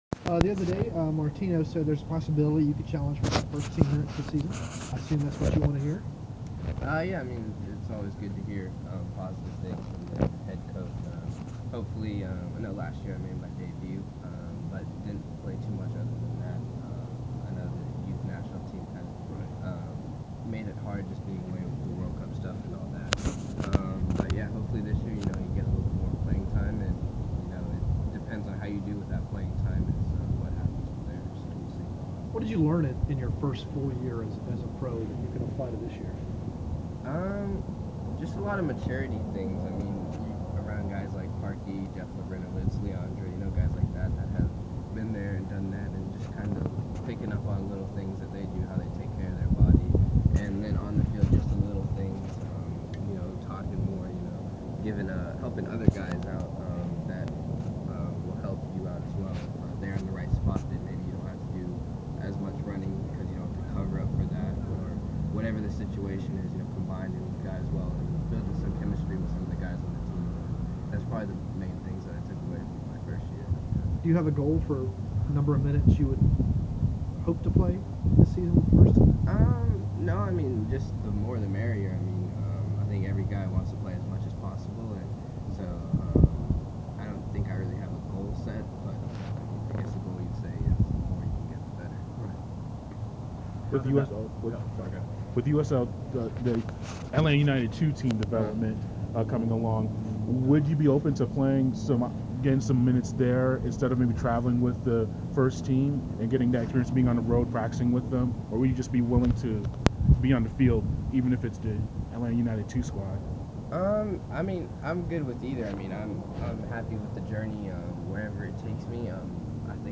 presser